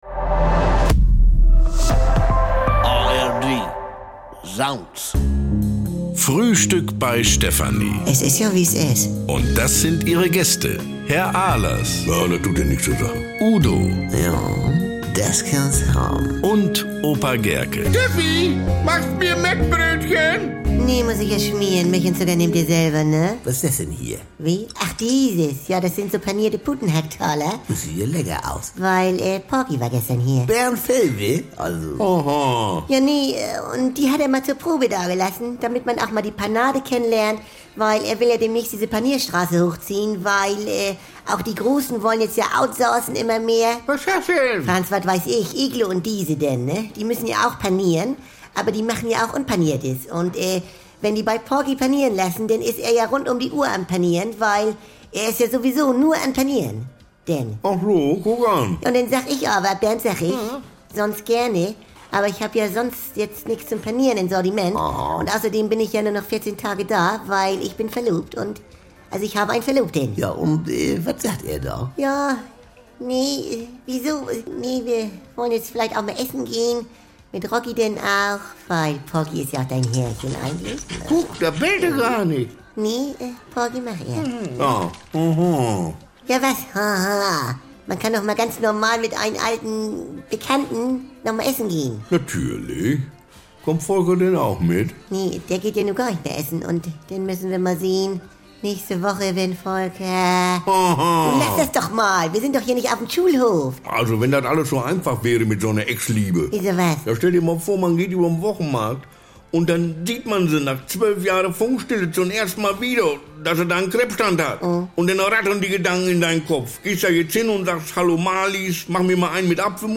und brummeliges Gemecker bekommt ihr jeden Tag im Radio oder